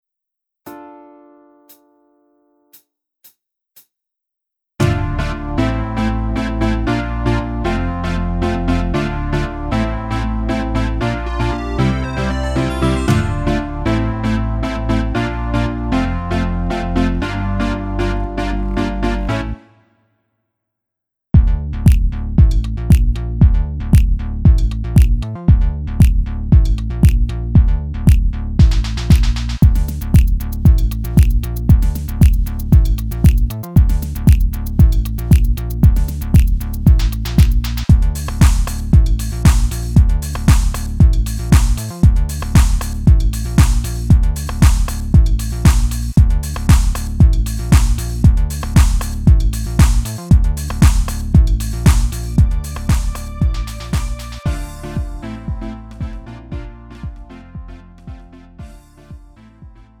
축가 및 결혼식에 최적화된 고품질 MR을 제공합니다!
음정 원키
장르 가요